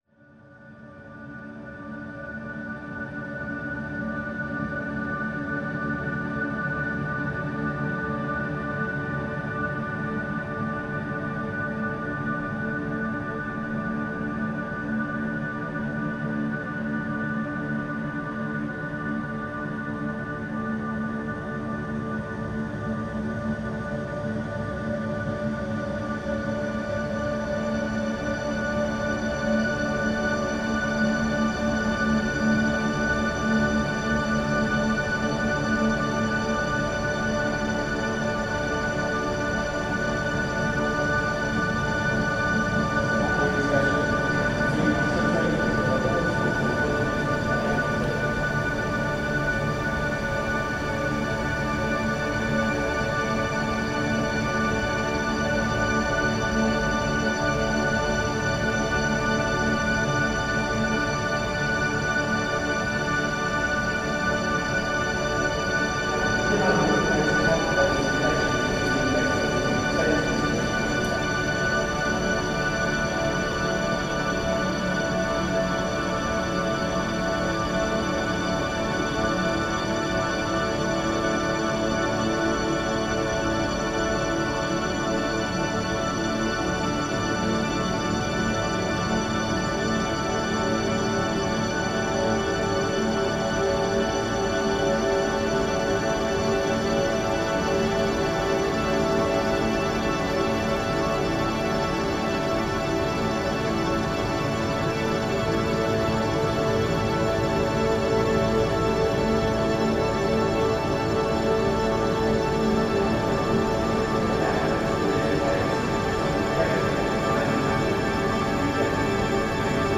Remix of the service from Southwark Cathedral, May 2014.